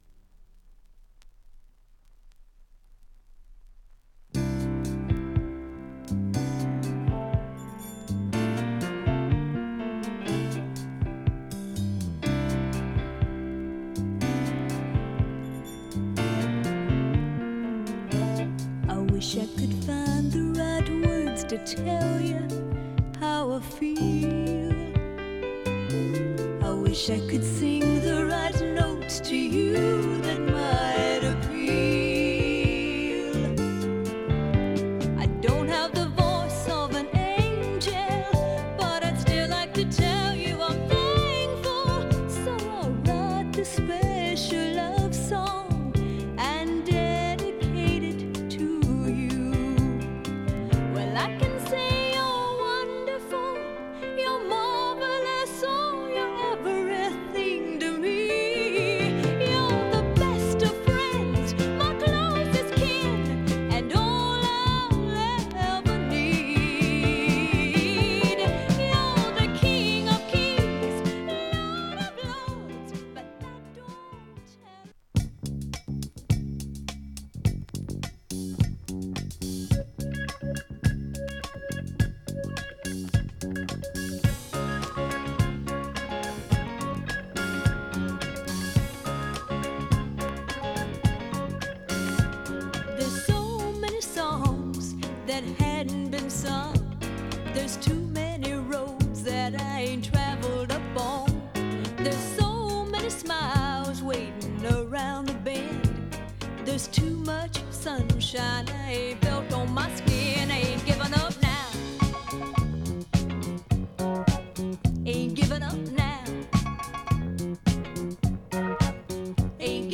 木漏れ日挿す洒脱さぶりもあり
エレピ使いなどメロウ・バラード